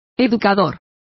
Also find out how educador is pronounced correctly.